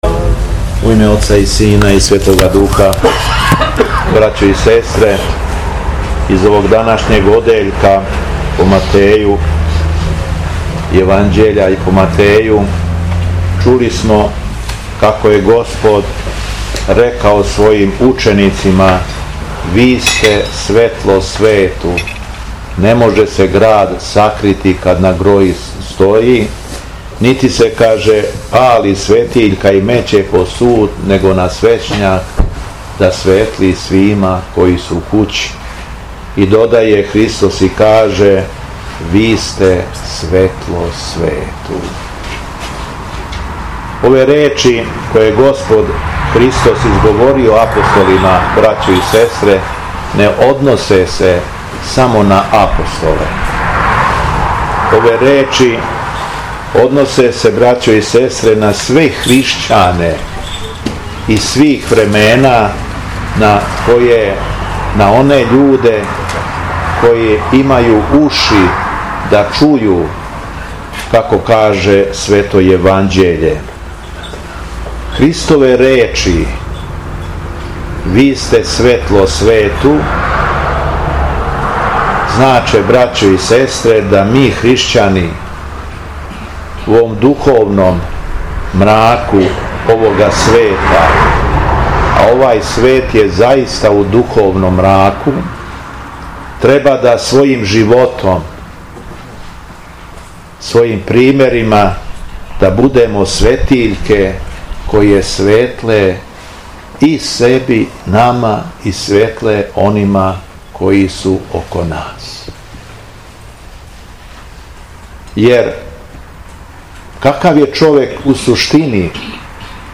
Беседа Његовог Високопреосвештенства Митрополита шумадијског г. Јована
По прочитаном Светом Јеванђељу Митрополит се обратио окупљеним верницима пригодном беседом.